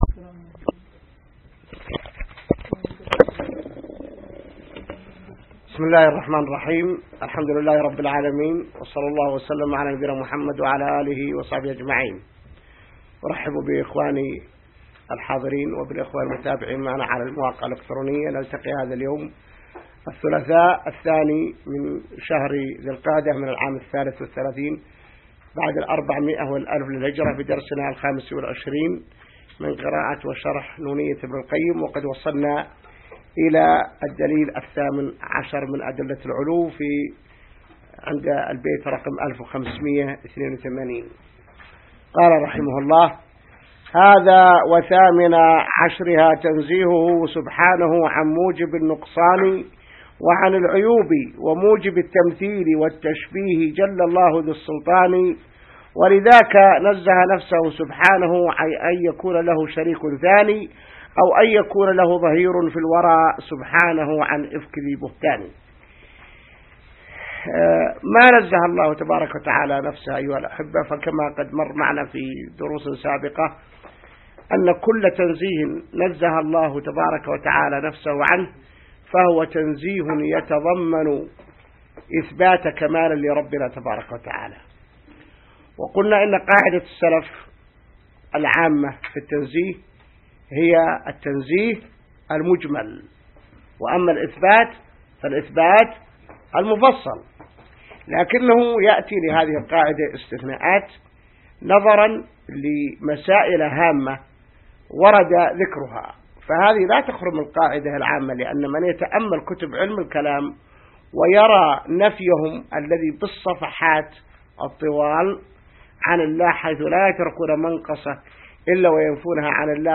الدرس 25 من شرح نونية ابن القيم